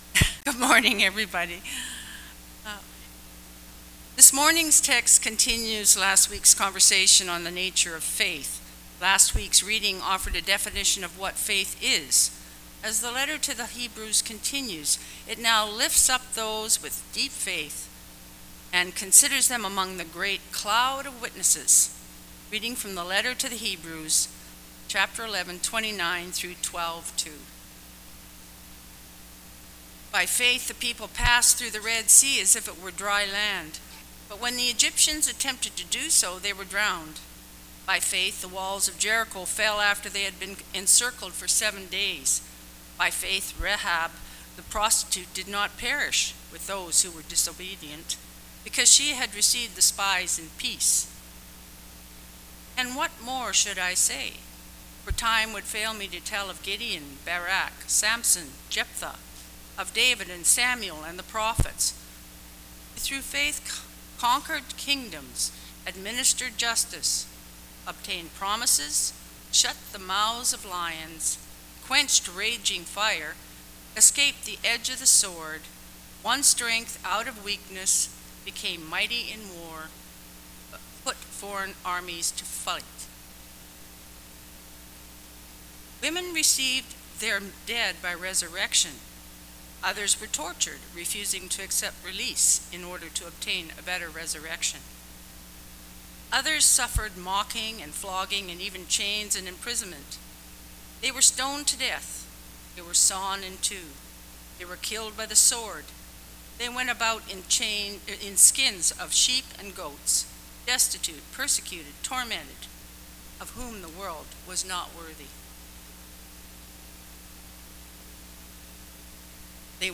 Sermons | Northwood United Church